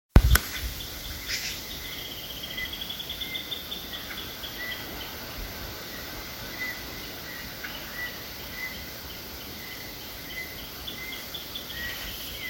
Chinchero Enano (Xiphorhynchus fuscus)
Nombre en inglés: Lesser Woodcreeper
Localidad o área protegida: Parque Nacional Iguazú
Condición: Silvestre
Certeza: Vocalización Grabada
Chinchero-enano.mp3